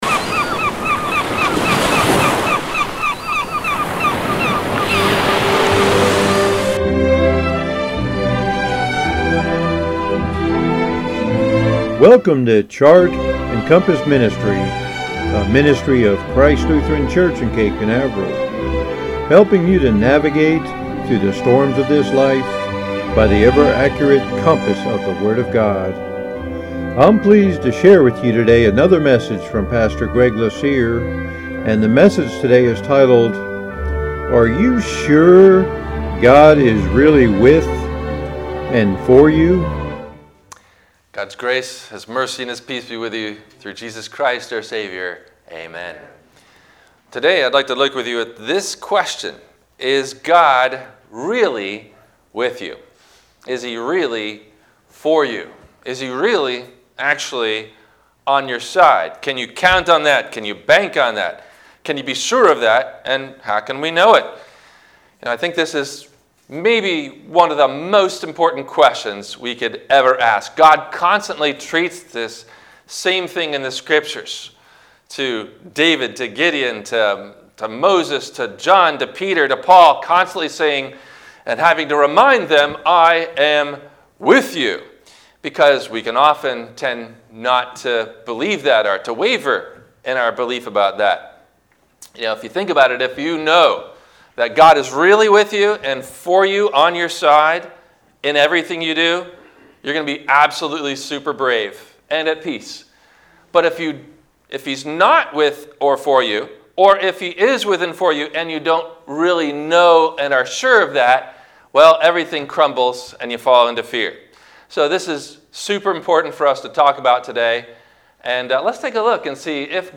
Are You Sure God Is Really With & For You? – WMIE Radio Sermon – December 07 2020
No questions asked before the Radio Message.